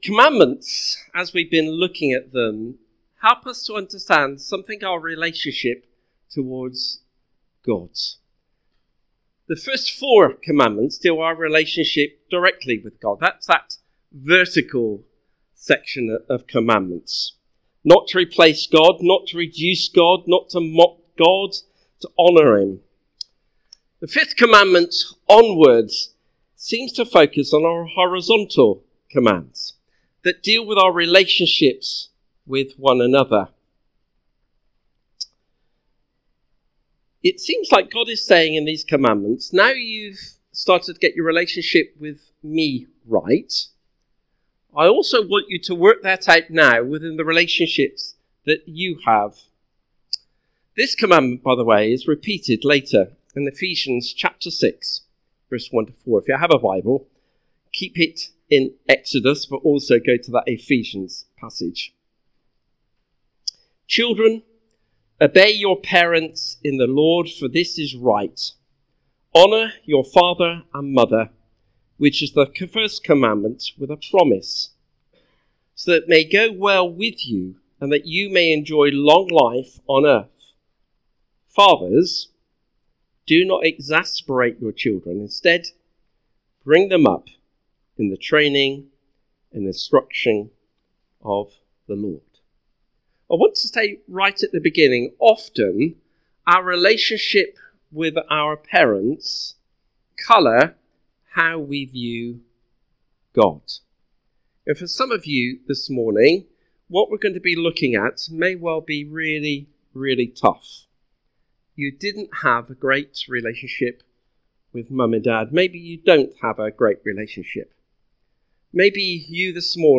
This sermon was preached on Sunday 8th June 2025 at a CBC Priory Street.